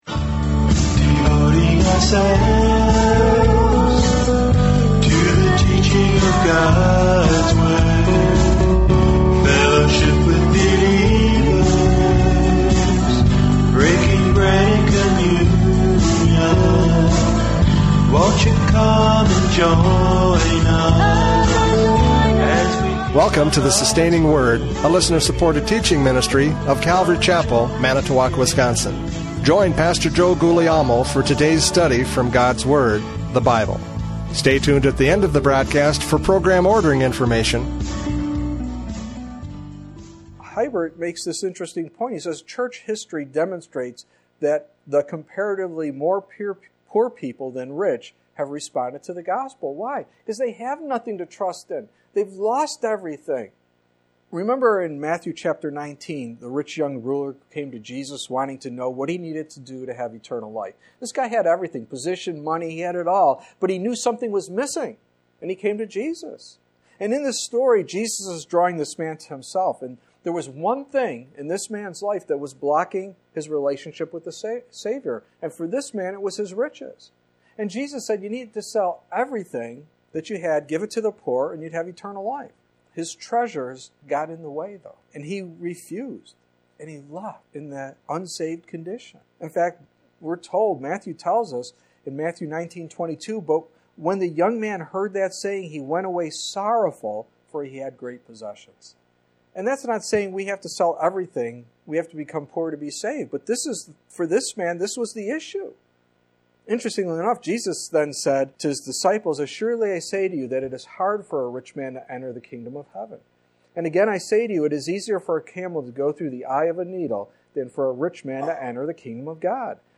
James 2:1-13 Service Type: Radio Programs « James 2:1-13 Faith is Proved by Love!